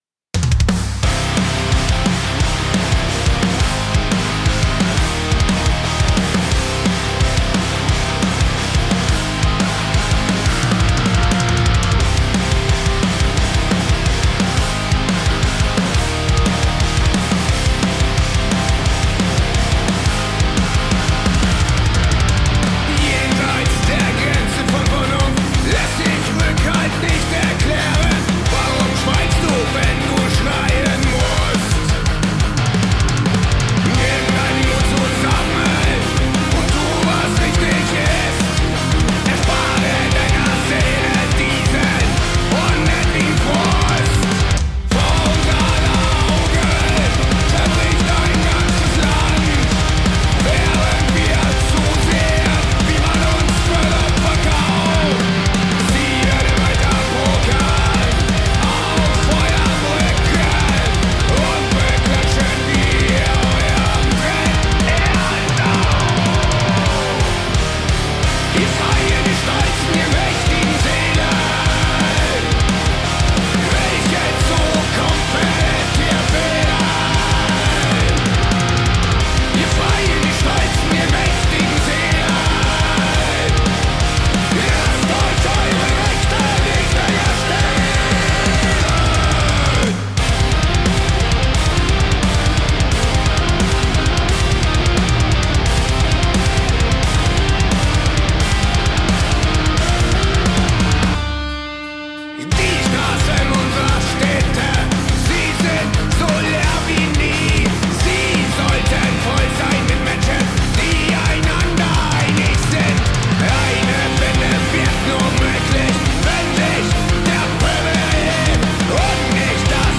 Kann man es Pop-Rock nennen?